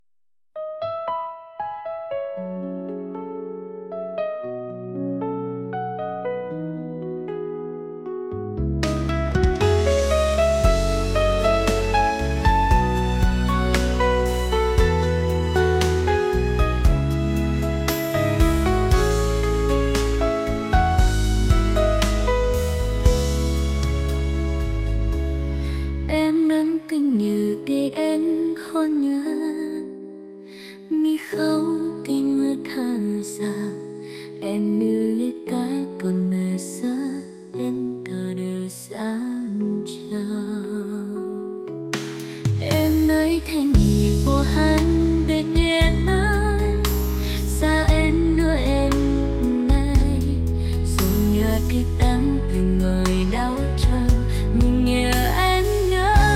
pop | romantic